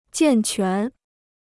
健全 (jiàn quán): robust; sound.